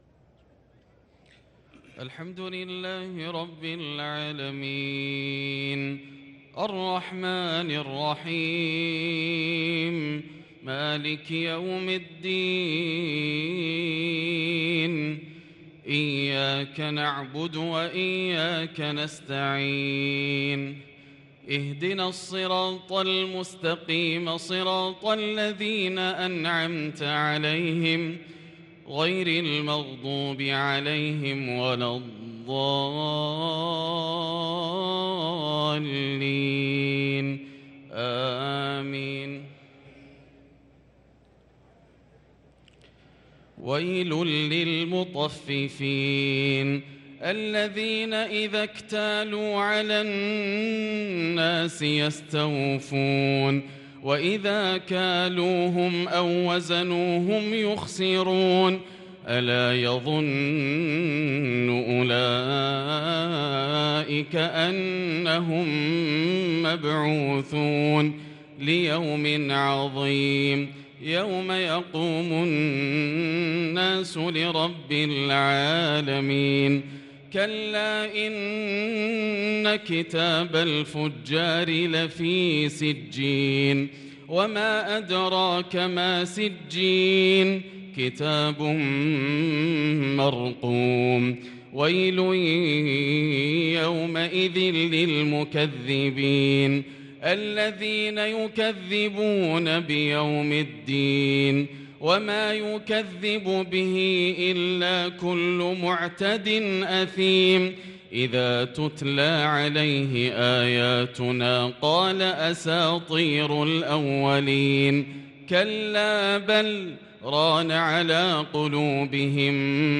صلاة الفجر للقارئ ياسر الدوسري 4 ذو القعدة 1443 هـ
تِلَاوَات الْحَرَمَيْن .